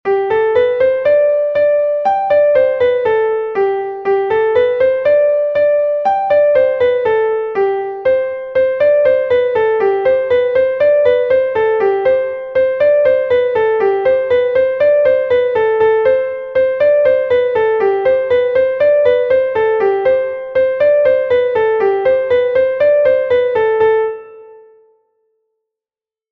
Laridé Koun II est un Laridé de Bretagne enregistré 1 fois par Koun